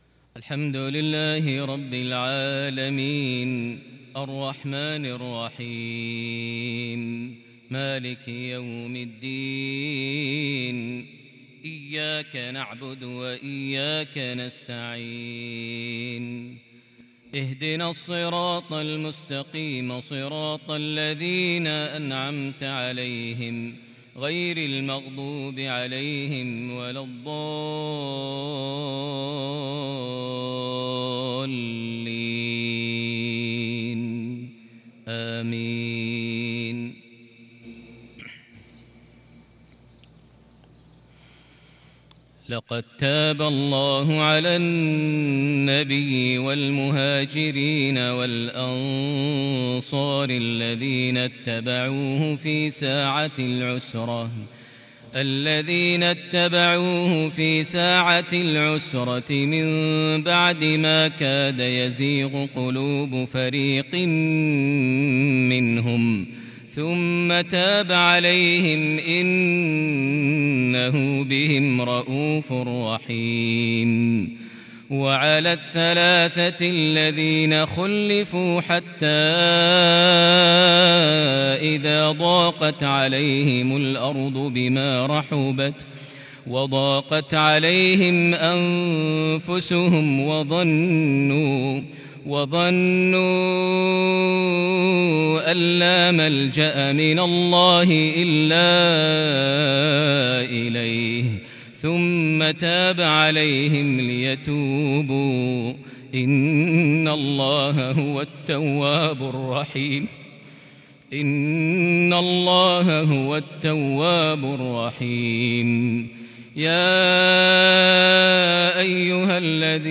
فجرية تحبيرية خاشعة لخواتيم سورة التوبة | الإثنين 19شوال 1442هـ > 1442 هـ > الفروض - تلاوات ماهر المعيقلي